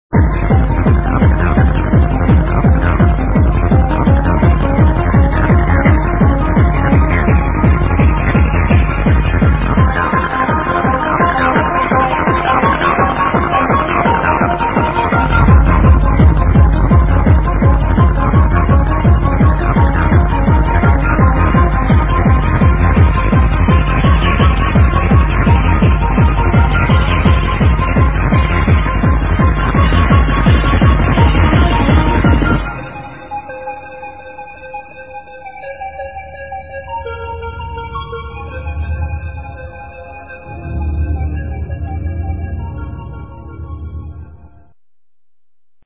this is an old rave track, i really need this one!!